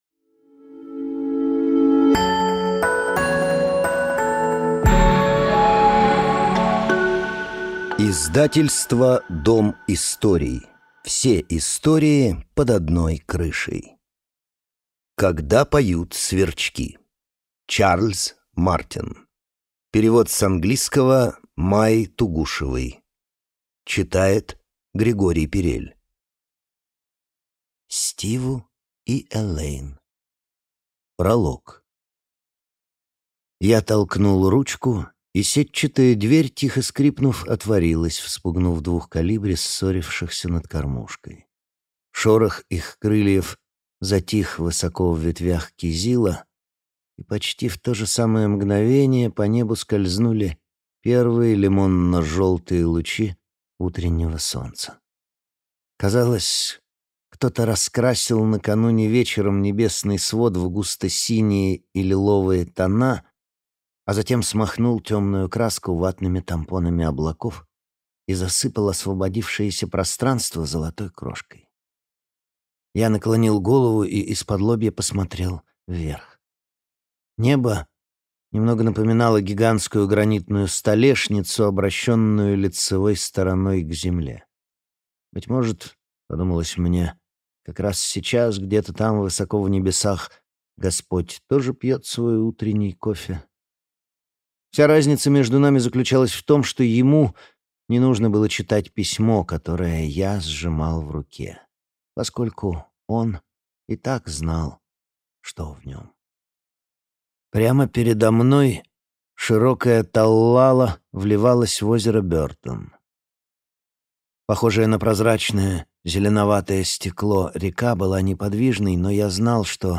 Аудиокнига Когда поют сверчки | Библиотека аудиокниг